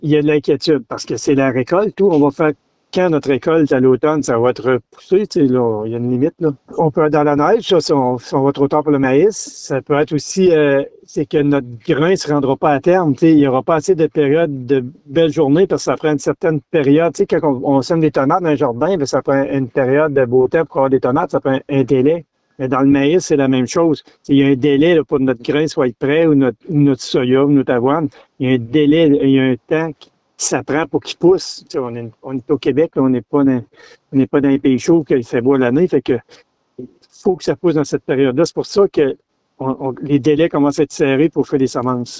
Le préfet de la MRC de Bécancour, qui est aussi producteur, Mario Lyonnais, a résumé la situation.